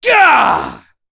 Chase-Vox_Attack4.wav